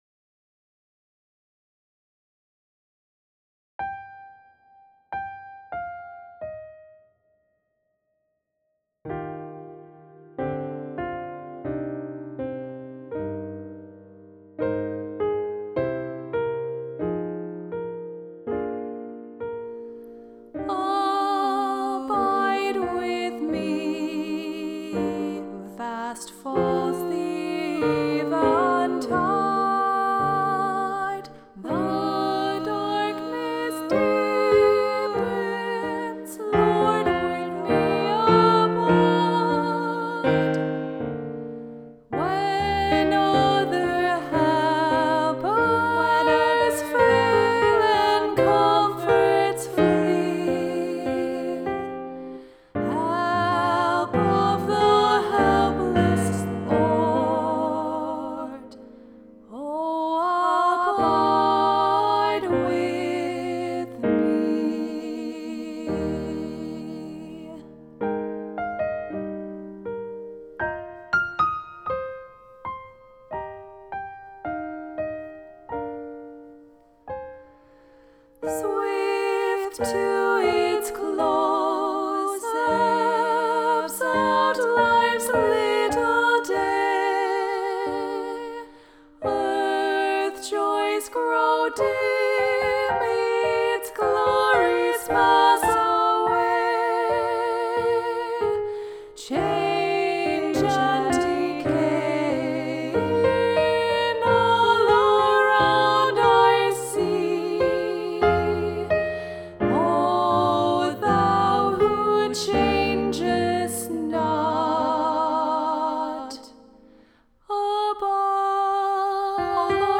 Keyboard, vocals